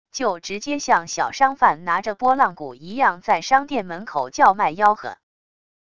就直接向小商贩拿着拨浪鼓一样在商店门口叫卖吆喝wav音频